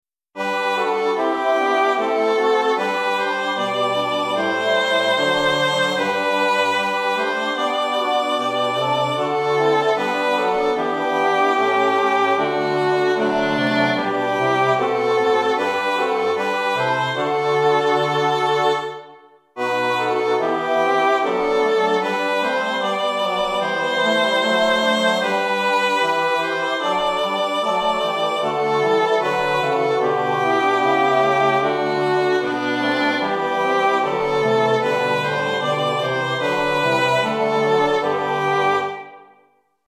Nu vilar folk och länder sop
nu vilar folk_sop.mp3